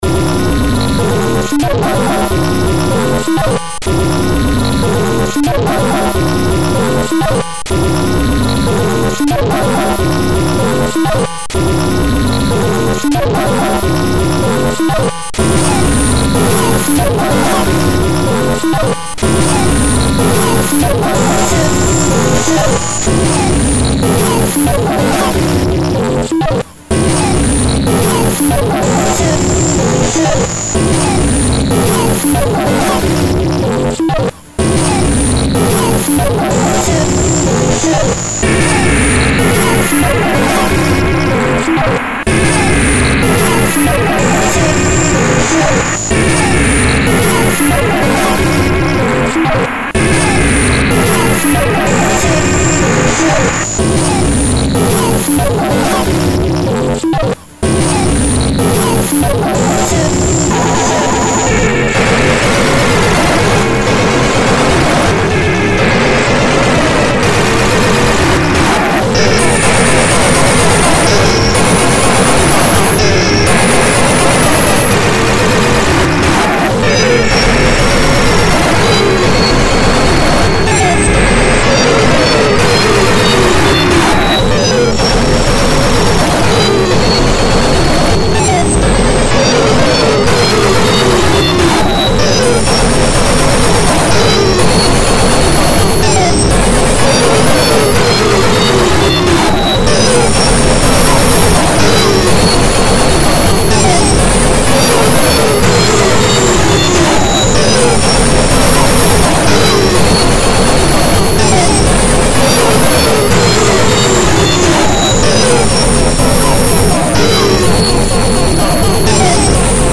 TWELVE TRACKS OF BEEPY, ATONAL,
POST-INDUSTRIAL/TECHNO/TRANCE/NOISE WEIRDNESS